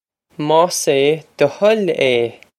Pronunciation for how to say
Maws ay duh huh-il ay
This is an approximate phonetic pronunciation of the phrase.